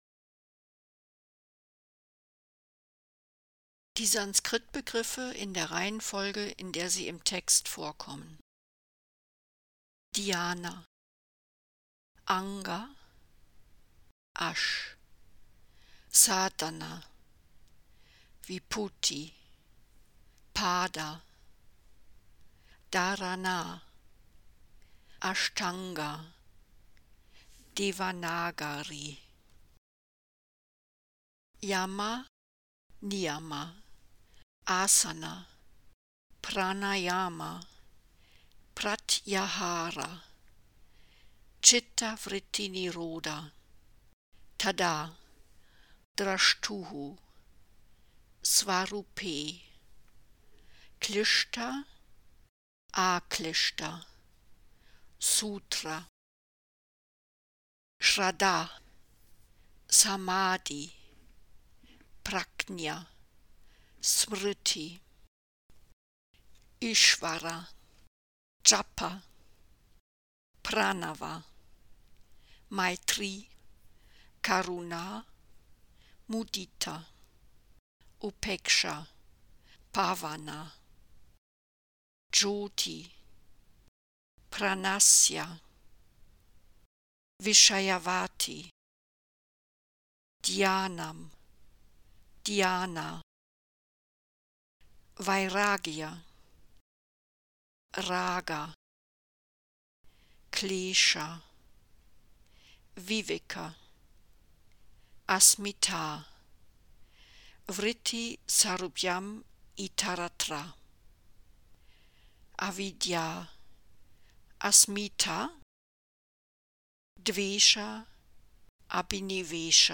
(Am Ende des Textes gibt es eine Audiodatei mit den Sanskritbegriffen)